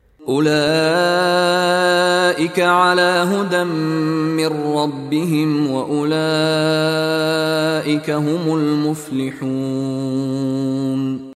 Contoh Bacaan dari Sheikh Mishary Rashid Al-Afasy
DIPANJANGKAN sebutan huruf Mad dengan kadar 4 atau 5 harakat